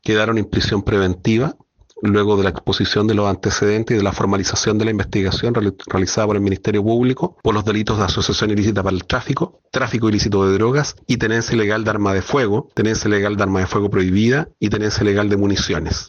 cu-formalizacion-1-fiscal.mp3